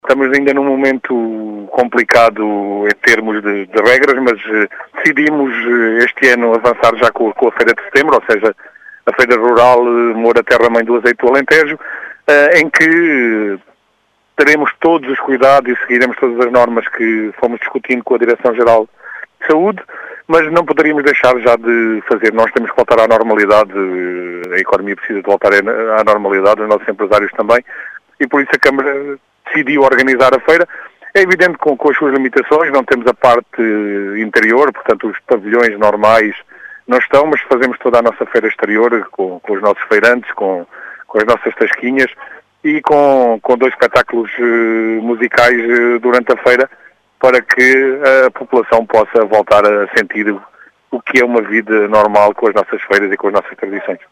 Este ano todas as atividades da Feira decorrem exclusivamente no recinto exterior, como explicou Manuel Bio, vereador da Câmara Municipal de Moura, que apesar do “momento complicado” que ainda se vive, defende o regresso á “normalidade”.